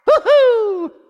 One of Mario's voice clips in Mario Party 6